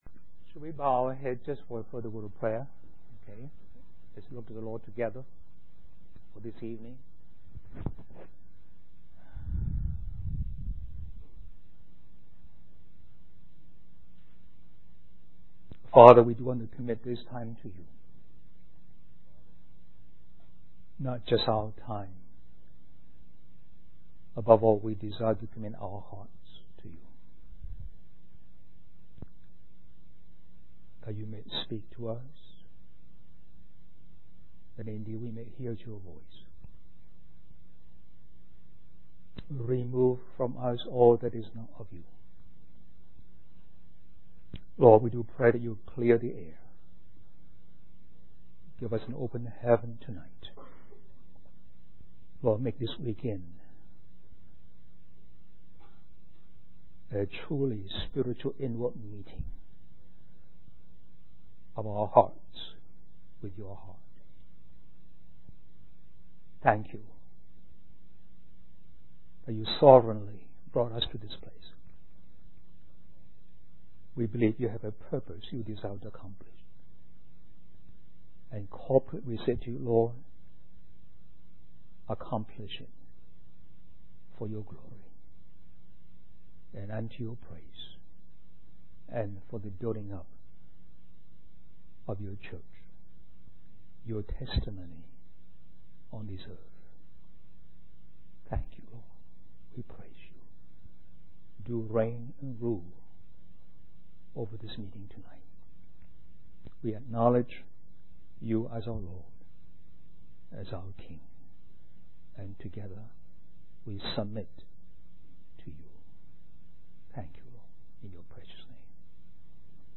In this sermon, the speaker addresses the issue of lacking an aggressive spirit in pursuing the Lord. He emphasizes the need to work diligently and ensure that our faith is genuine. The purpose of the weekend conference is to help each other discover the eternal things and have a clear direction in our Christian lives.